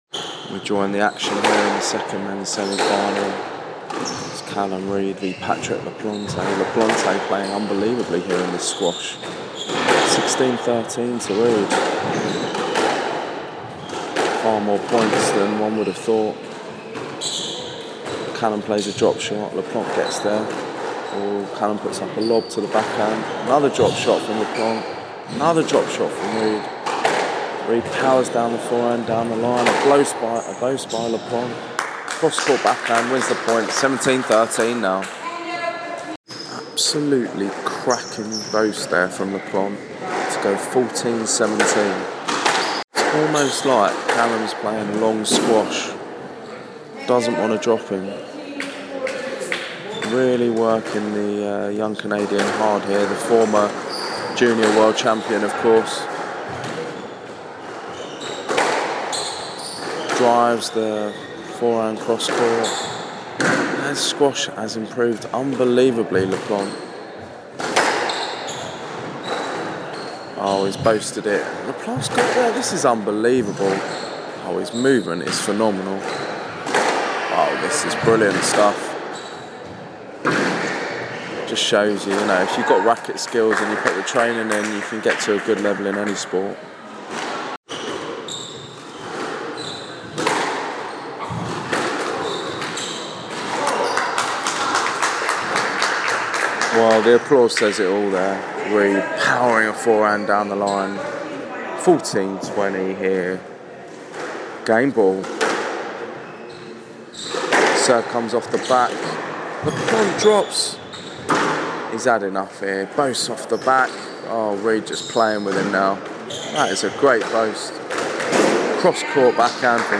Canadian open semi final Racketlon